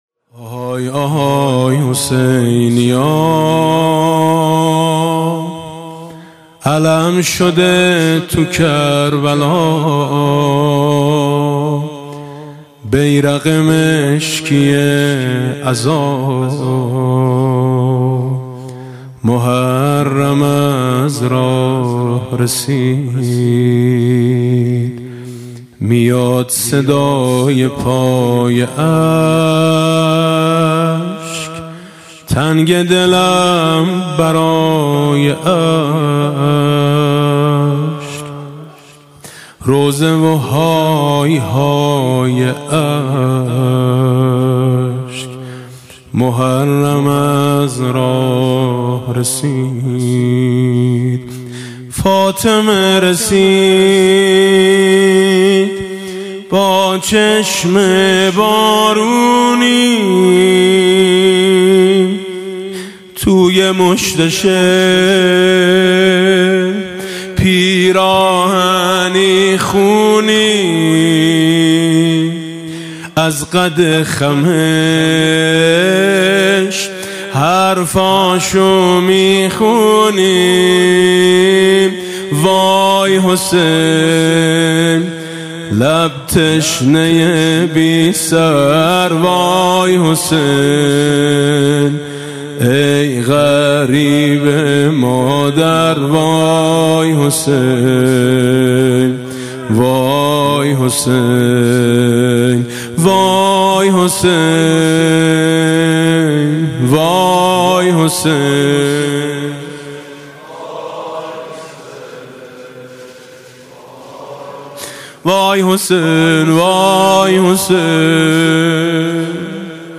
ویژه شب اول محرم
دانلود مداحی شب اول محرم میثم مطیعی اخبار مرتبط دومین اجلاس خیرین هیئت در جوار حرم امام رضا (ع) برگزار شد.